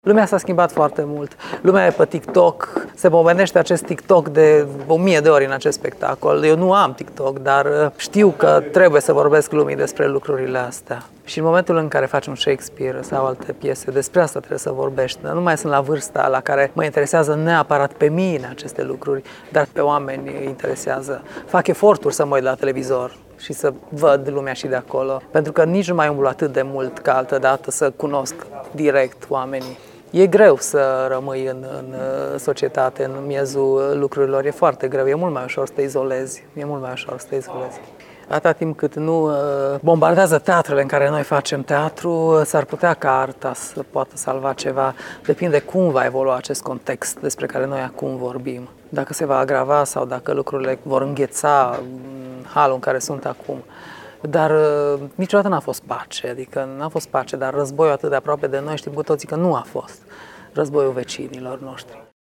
Iată un scurt fragment din interviul acordat de Radu Afrim, autor al scenariului, regiei artistice și universului sonor: